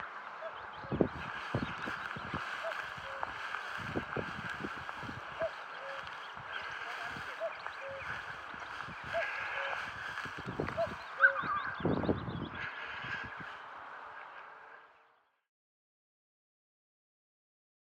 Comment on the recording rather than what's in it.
Heard this while out round a local reservoir this morning… first Cuckoos (at least 3 of them) I’ve heard near us for 15 years or more so very happy + gives me a chance for a sneaky bump 🐦😊